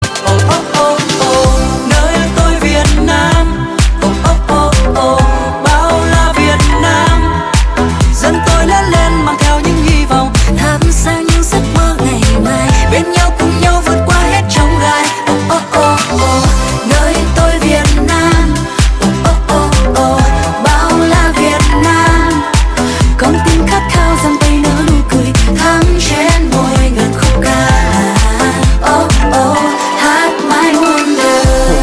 Thể loại nhạc chuông: Nhạc trữ tình